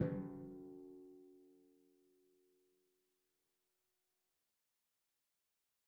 Timpani5_Hit_v3_rr1_Sum.wav